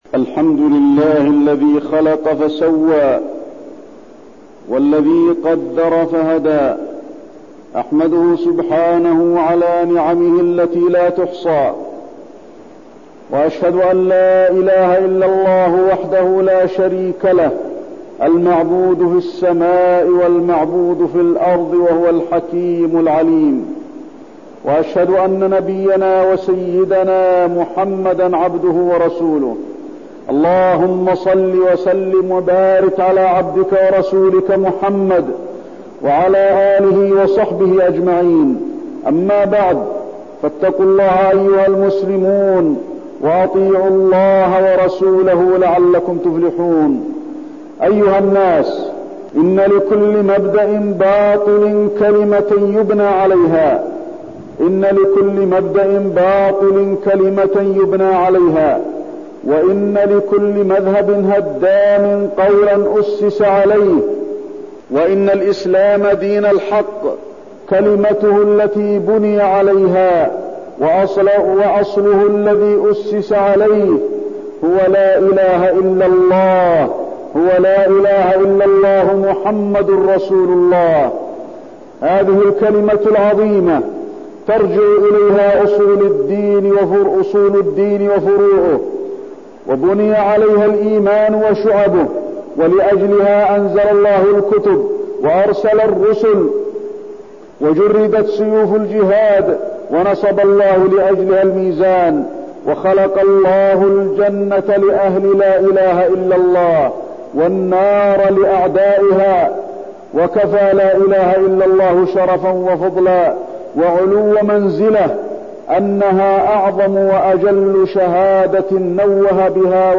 تاريخ النشر ٨ صفر ١٤١٠ هـ المكان: المسجد النبوي الشيخ: فضيلة الشيخ د. علي بن عبدالرحمن الحذيفي فضيلة الشيخ د. علي بن عبدالرحمن الحذيفي شهادة أن لا إله إلا الله